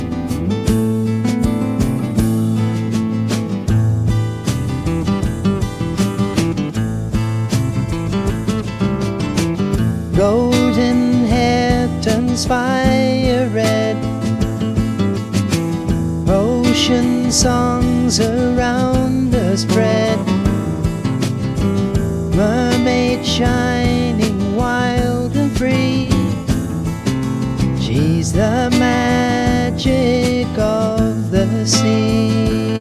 Well let’s try creating a short AI song.
These were Folk and Soft Rock.